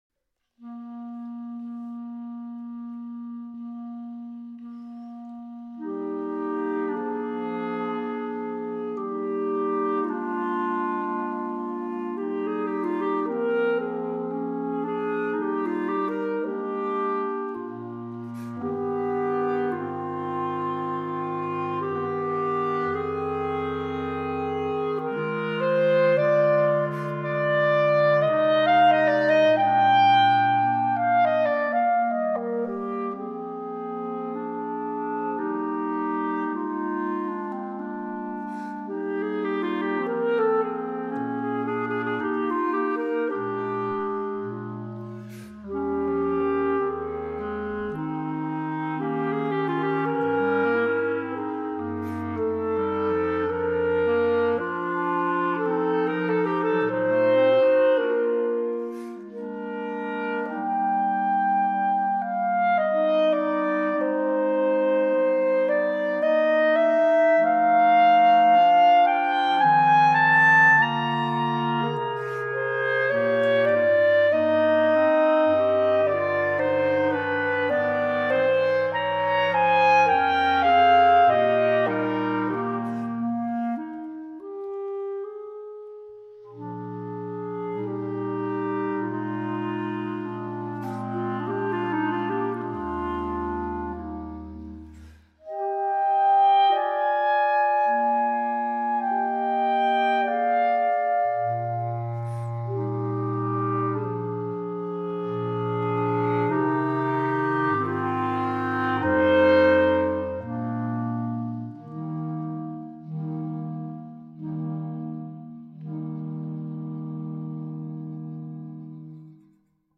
Gattung: für Klarinettenquartett
Besetzung: Instrumentalnoten für Klarinette
is a subtle piece of music